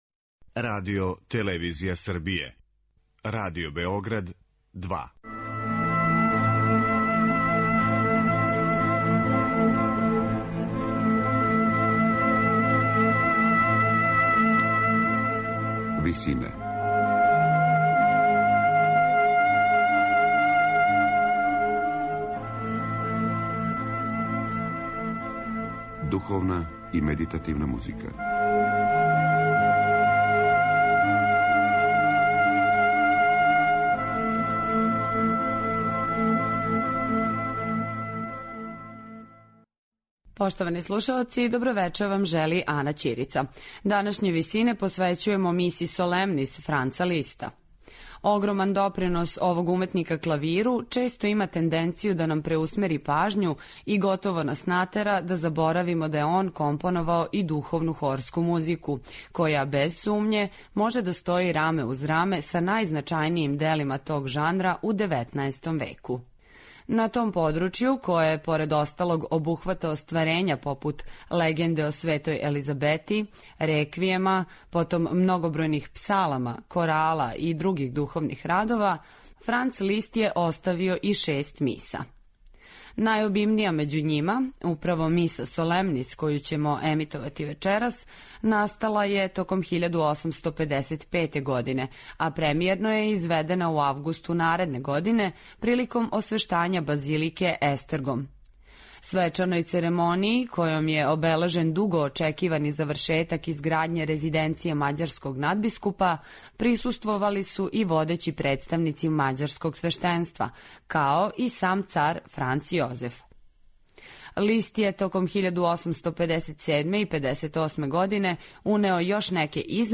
духовну хорску музику